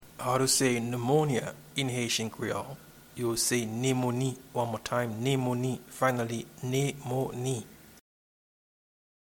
Pronunciation and Transcript:
Pneumonia-in-Haitian-Creole-–-Nemoni.mp3